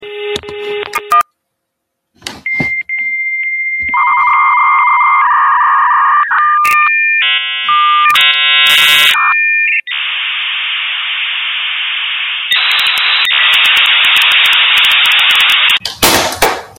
Dial Up Homemade Sound Button - Free Download & Play